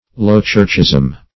Low-churchism \Low"-church`ism\, n. The principles of the low-church party.